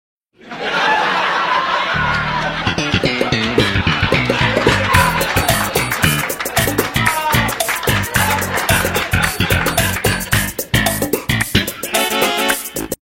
Seinfield Laugh Track